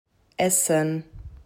z.B. essen
essen.m4a